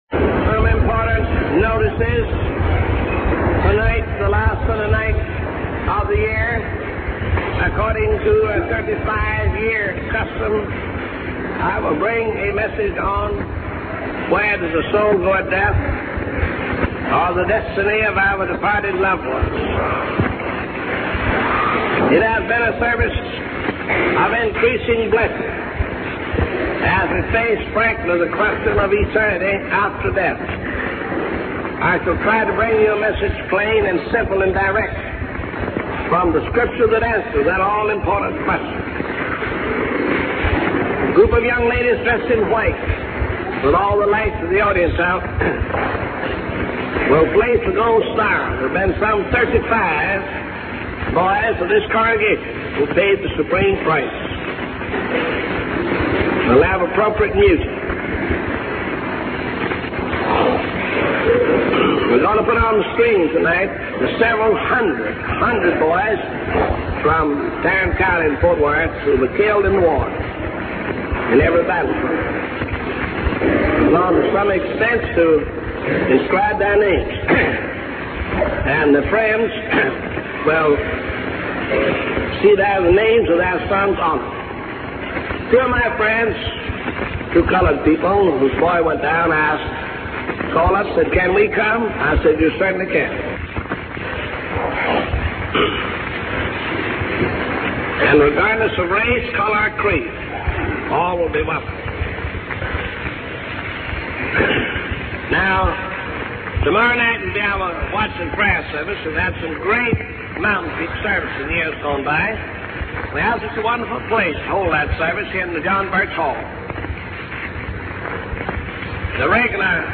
In this sermon, the preacher addresses the concept of change in the world and the challenges that people face, such as conflict, anxiety, fear, and poverty. He shares personal experiences of witnessing the pain and loss of others, including the burial of a baby and the death of young people. The preacher emphasizes the importance of finding joy and rejoicing in the midst of these difficulties, as believers are called the children of God.